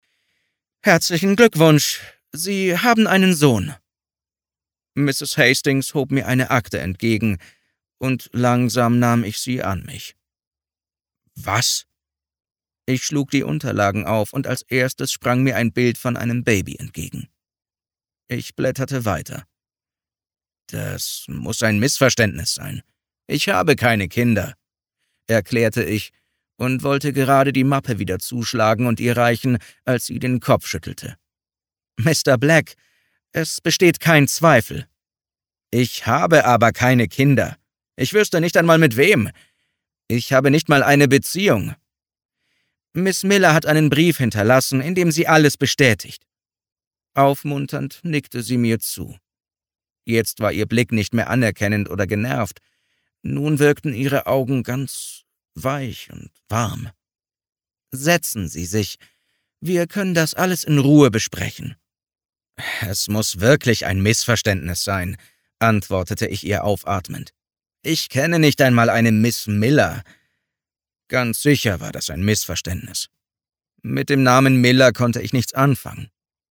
Infos zum Hörbuch